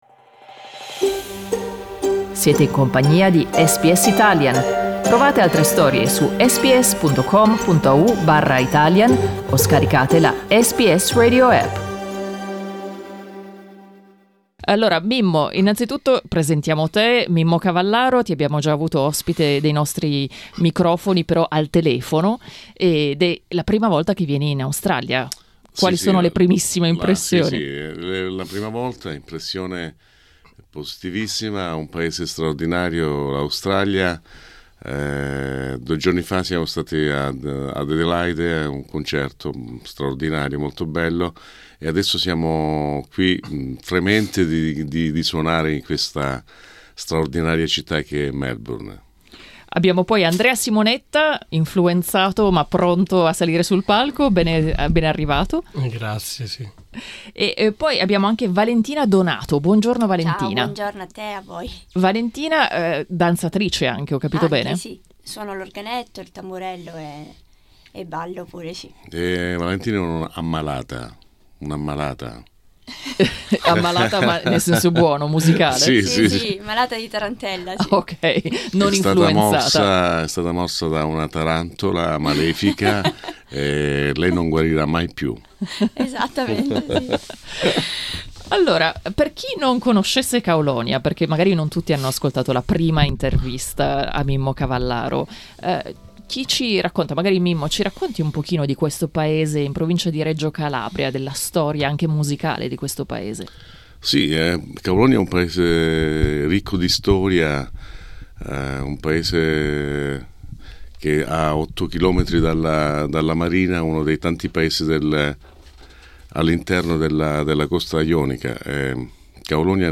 live interview and performance in our Melbourne studios.
Calabrian traditional folk music